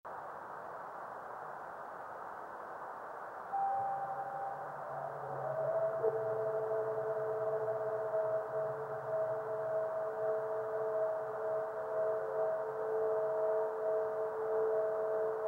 video and stereo sound:
This clip is the initial head echo of the fireball only.